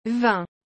A pronúncia certa é algo como /vɛ̃/, com aquele som nasal bem típico do francês.
Como pronunciar vin corretamente?
O som de vin no francês é nasal, parecido com um “vã” bem fechado.
• Não pronuncie o “n” no final, ele só serve para nasalizar a vogal.
• O som correto fica entre “vã” e “vẽ”, mas sem exagerar.